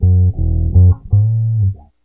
BASS 29.wav